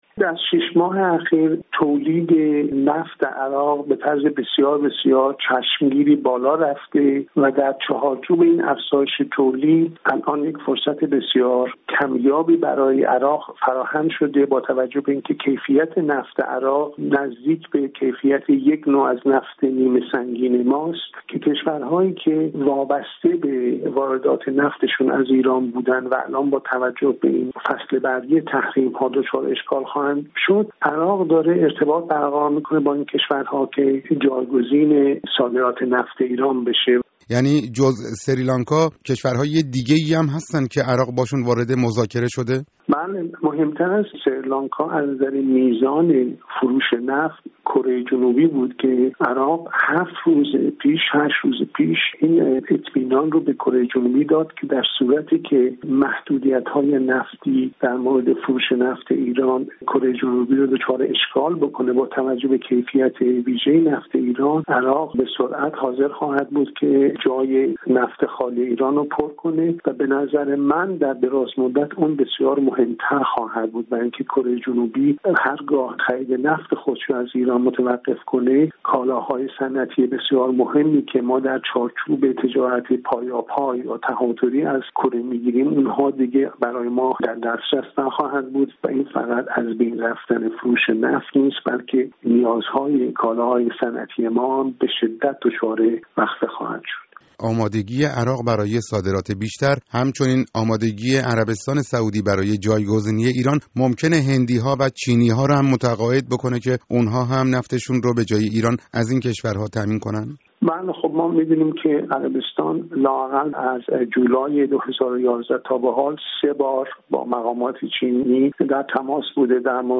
گفت‌و گوی